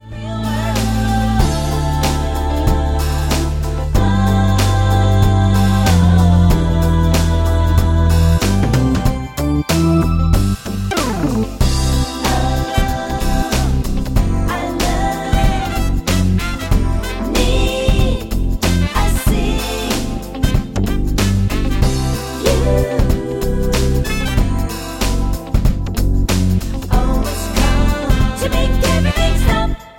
MPEG 1 Layer 3 (Stereo)
Backing track Karaoke
Pop, Musical/Film/TV, 2000s